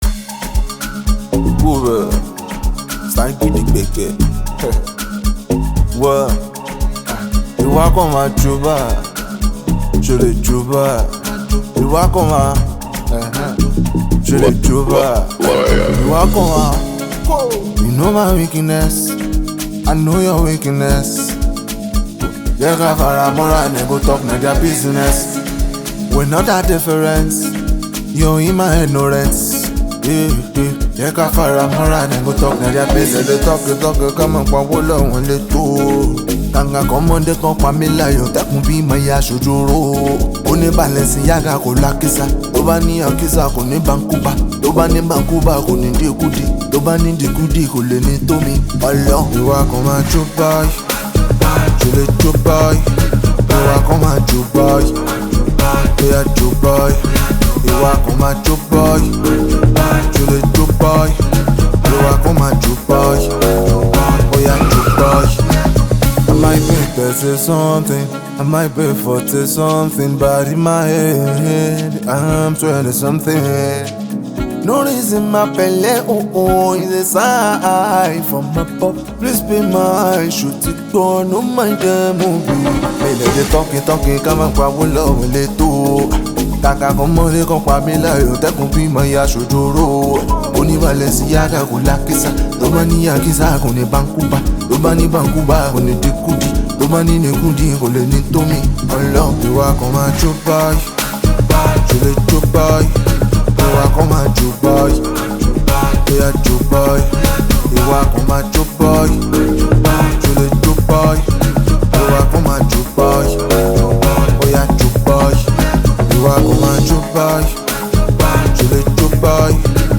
Nigerian Yoruba Fuji track
especially if you’re a lover of Yoruba Fuji Sounds